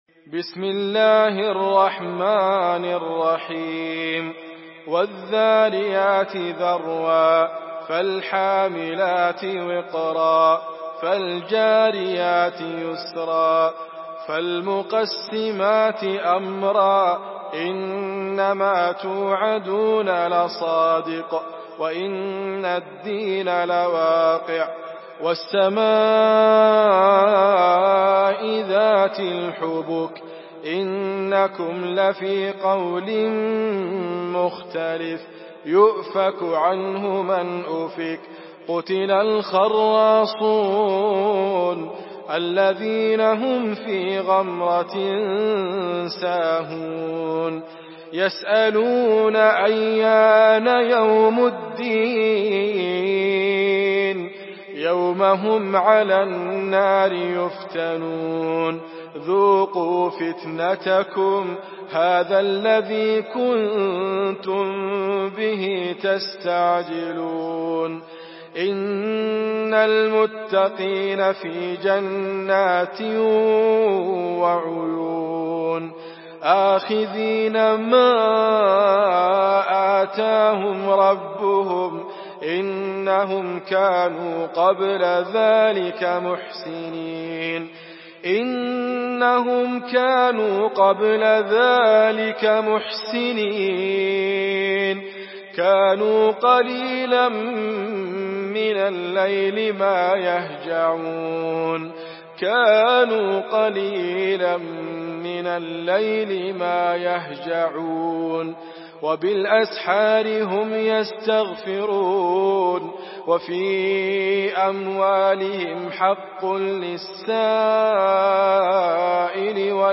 Surah Ad-Dariyat MP3 in the Voice of Idriss Abkar in Hafs Narration
Murattal Hafs An Asim